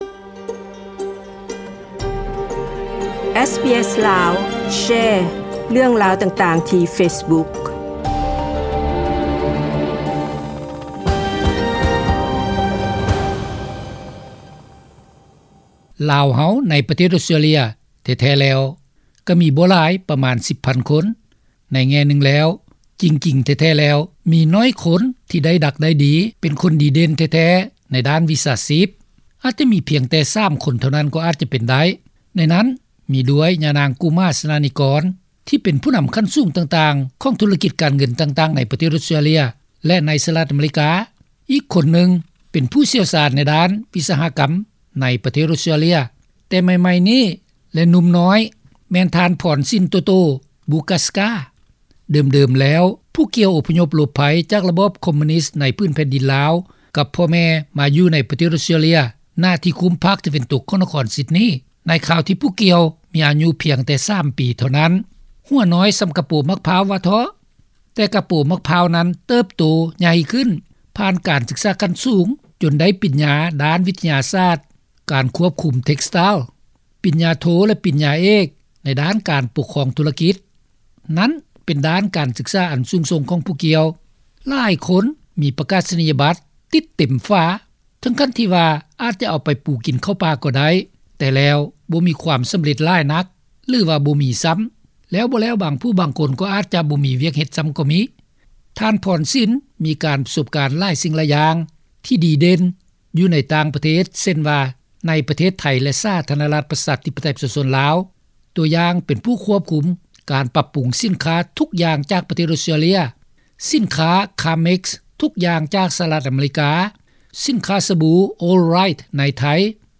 ດັ່ງການສຳພາດທ່ານຊີ້ບອກໃຫ້ຮູ້ ເຫັນວ່າ :